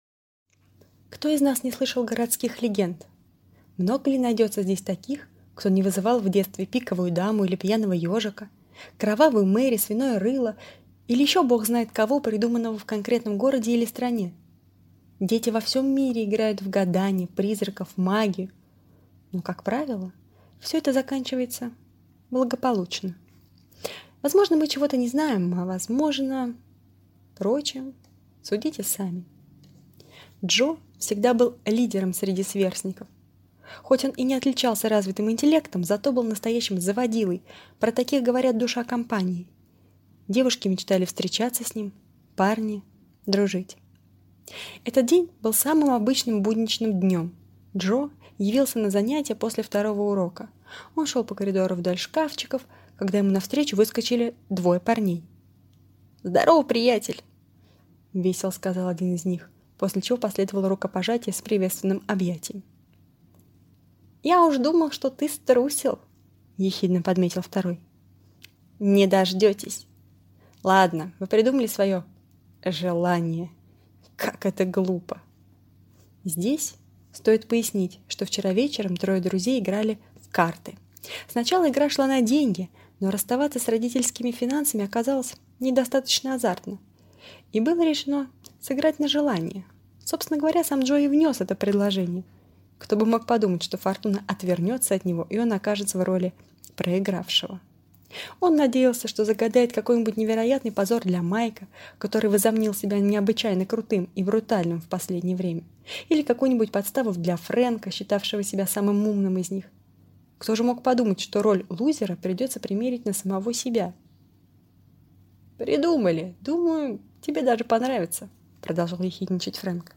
Аудиокнига Тайна 15 этажа | Библиотека аудиокниг